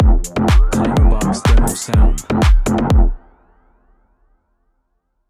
“Midnight Pulse” Clamor Sound Effect
Can also be used as a car sound and works as a Tesla LockChime sound for the Boombox.